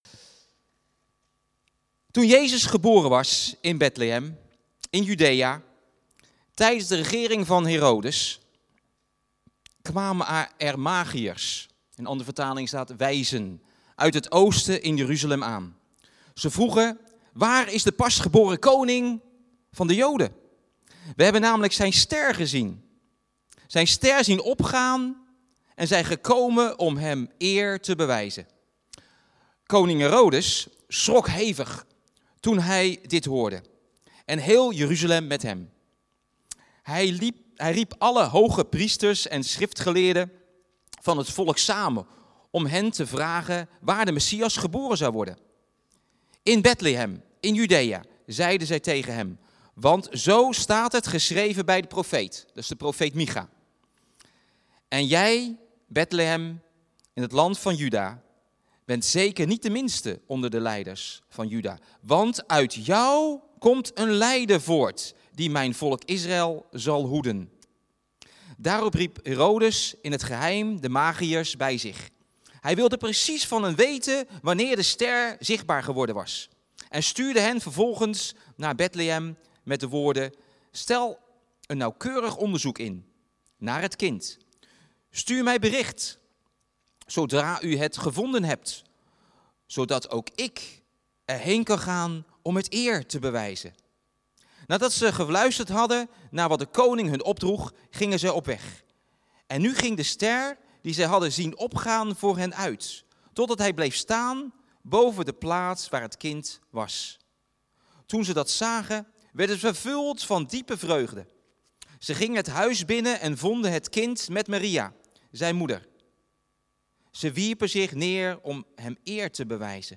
Kerstdienst